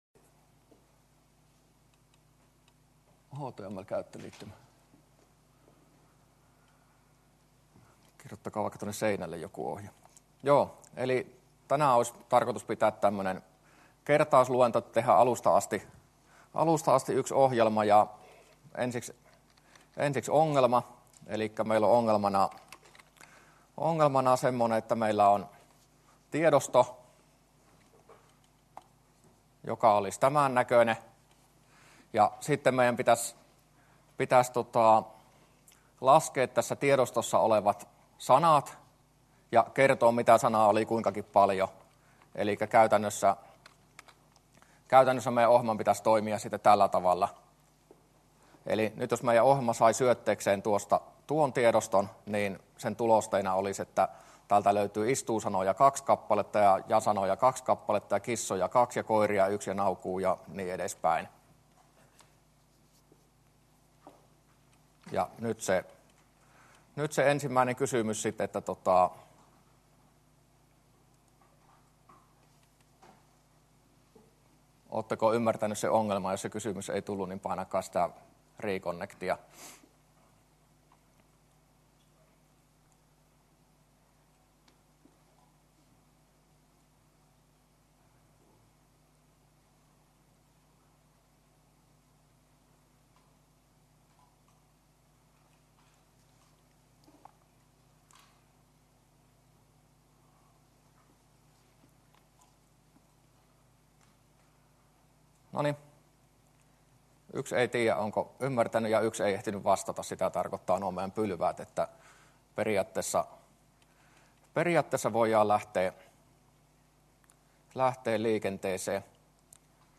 luento19a